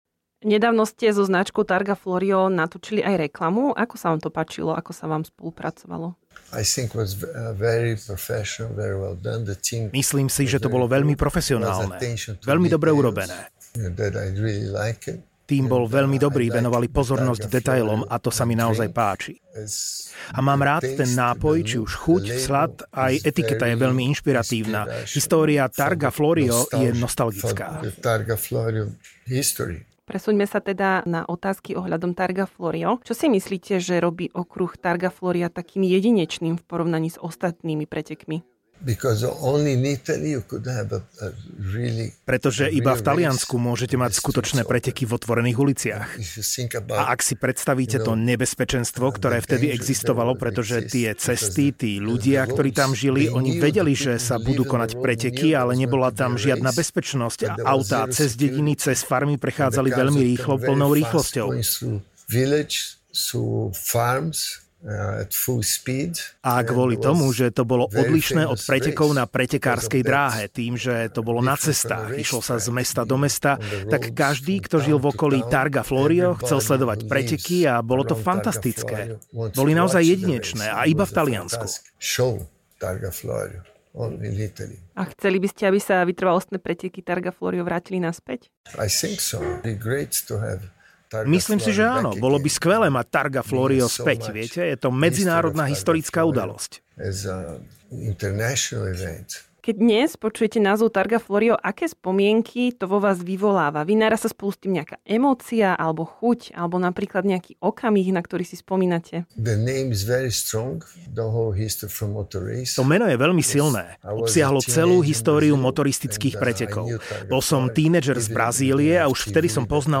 Legendárny majster sveta Formuly 1, Emerson Fittipaldi v rozhovore porozprával o ikonických pretekoch Targa Florio a prečo by bol rád, keby sa slávne vytrvalostné preteky vrátili späť.